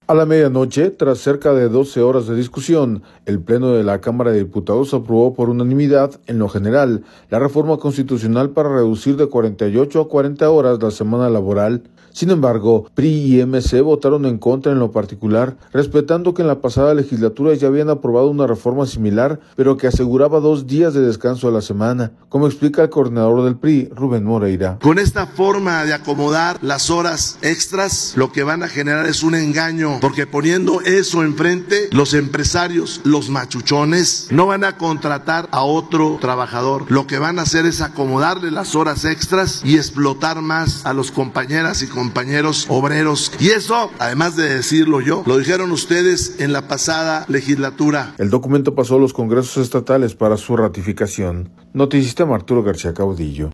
audio A la media noche, tras cerca de doce horas de discusión, el Pleno de la Cámara de Diputados aprobó por unanimidad, en lo general, la reforma constitucional para reducir de 48 a 40 horas la semana laboral. Sin embargo, PRI y MC votaron en contra en lo particular, respetando que en la pasada legislatura ya habían aprobado una reforma similar pero que aseguraba dos días de descanso a la semana, como explica el coordinador del PRI, Rubén Moreira.